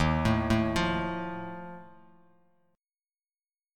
Ebsus2b5 chord